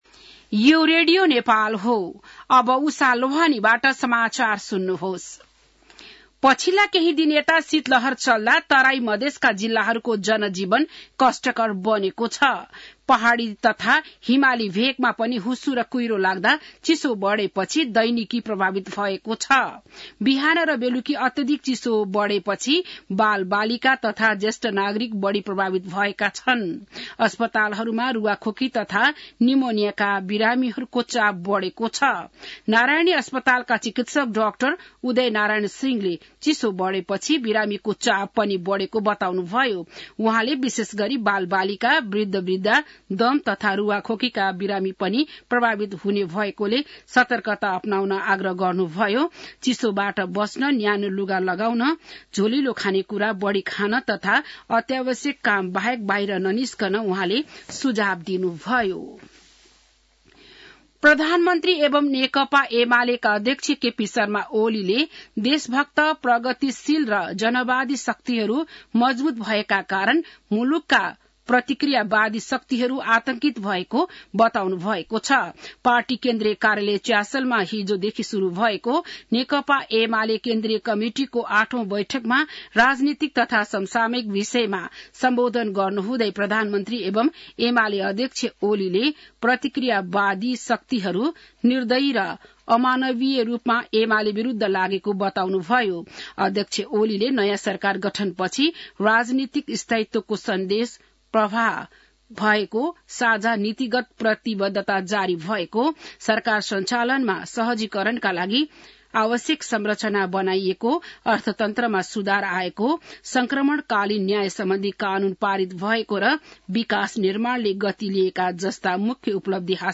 बिहान १० बजेको नेपाली समाचार : २३ पुष , २०८१